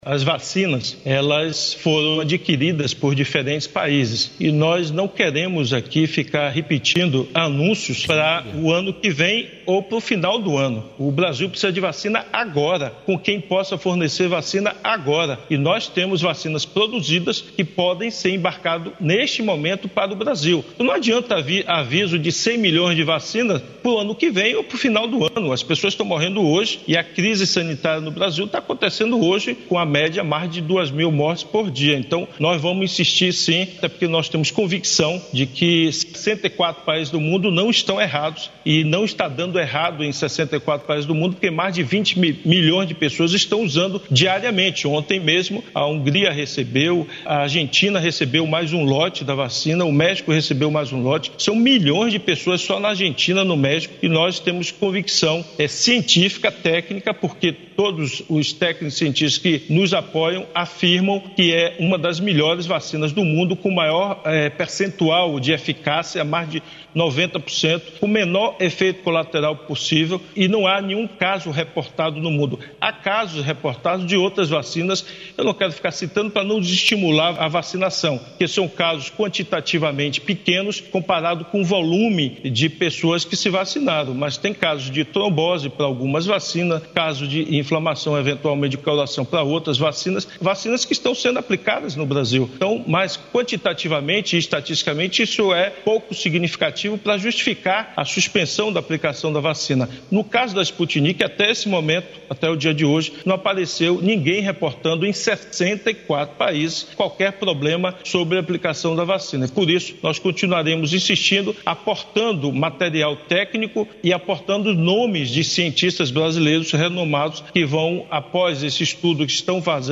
O governador Rui Costa disse nesta terça-feira (04), em entrevista à GloboNews (áudio abaixo), que o Governo da Bahia vai continuar insistindo pela liberação de importação e uso da vacina russa Sputinik V junto à Agência Nacional de Vigilância Sanitária (Anvisa). Rui destacou que a vacina já está sendo utilizada em 64 países, possui um dos maiores percentuais de eficácia comprovados e sem nenhum caso de efeito colateral.